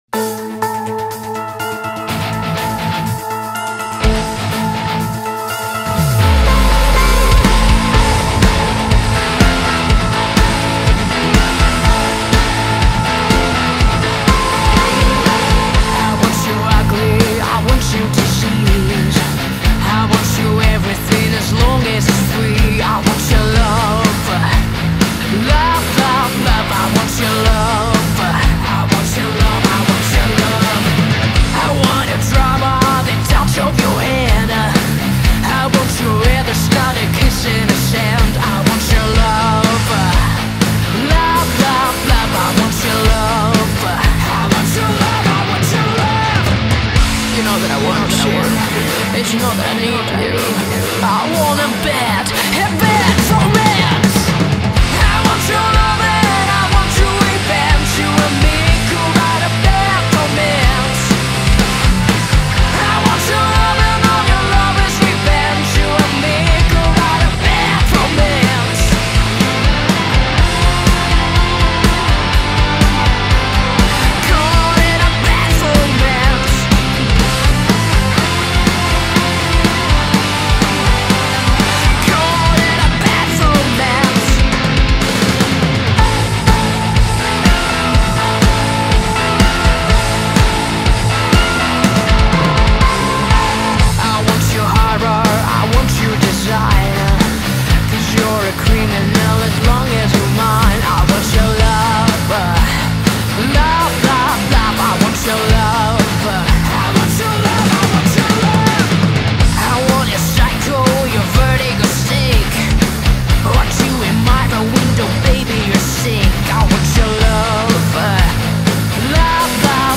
full band metal cover